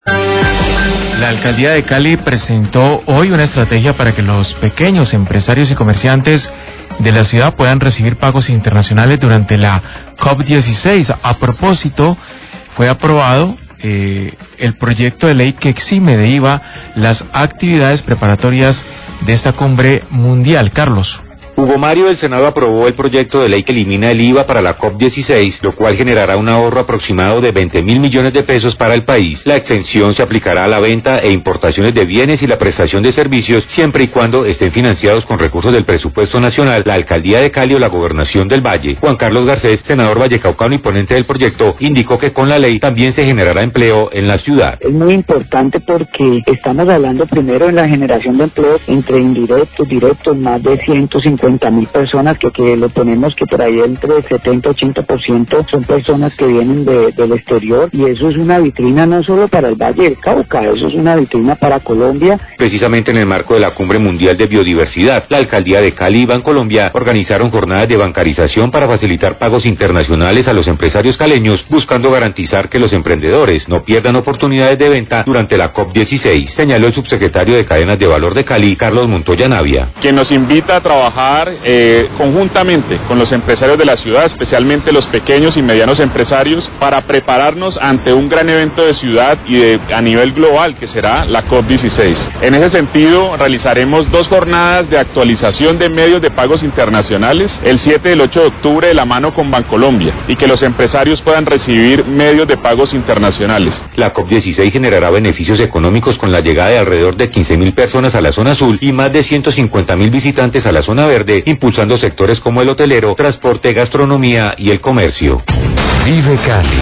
Radio
El Senado de la República aprobó la exención del cobro para la COP16. La Alcaldía de Cali y Bancolombia realizron jornadas de bancarización  con emprendedores y pequeños comerciantes caleños para que puedan recibir pagos en dólares durante este evento. Declaraciones de Carlos Montoya, Subsecretario de Cadena de Valores de la alcaldía.